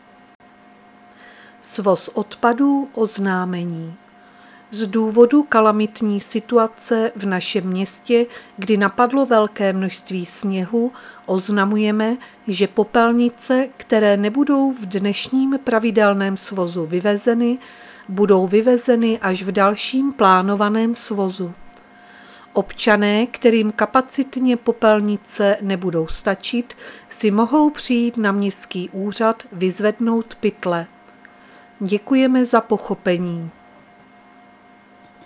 Záznam hlášení místního rozhlasu 16.12.2022